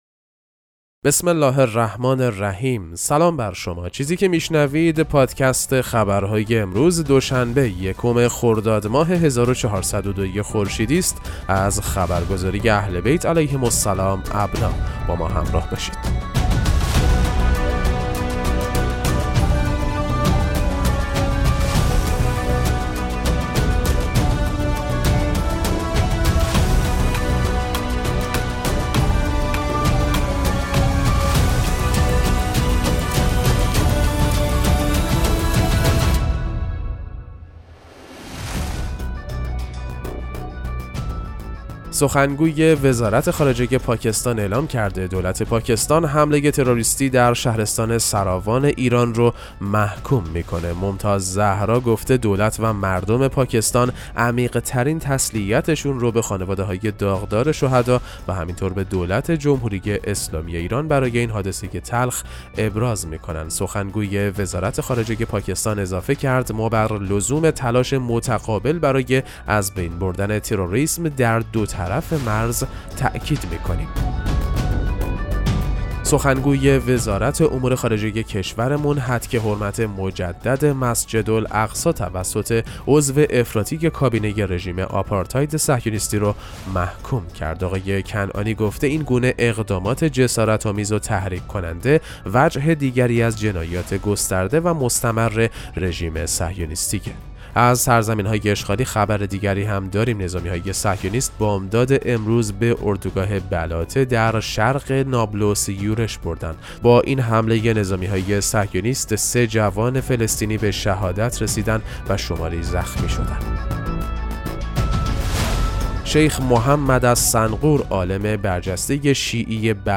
پادکست مهم‌ترین اخبار ابنا فارسی ــ یکم خرداد 1402